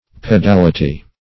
Pedality \Pe*dal"i*ty\, n. The act of measuring by paces.